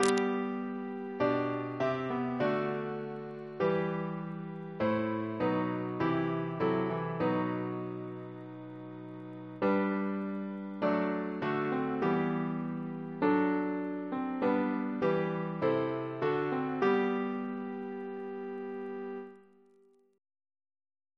CCP: Chant sampler
Double chant in G Composer: James Turle (1802-1882), Organist of Westminster Abbey Reference psalters: ACB: 128; ACP: 35 266; CWP: 60; H1940: 659; H1982: S209; OCB: 50; PP/SNCB: 30; RSCM: 148